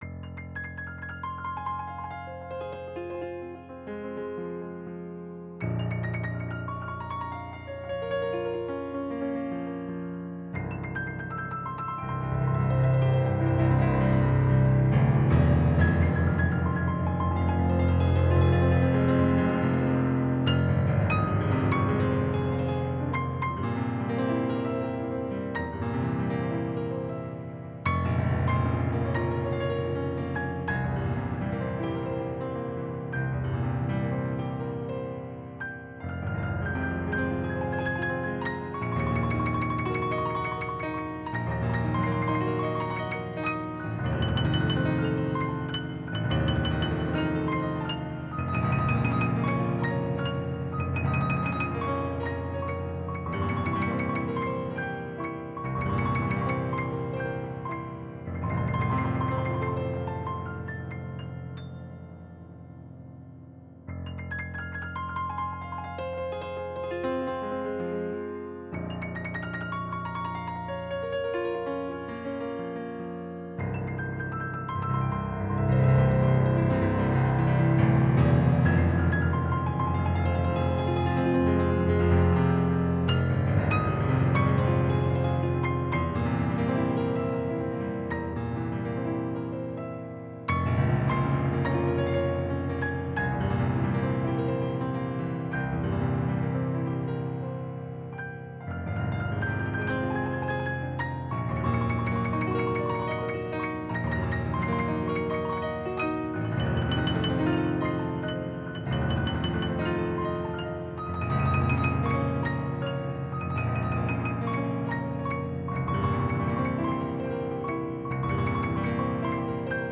Mein Haupt-Hobby, Klavierkompositionen: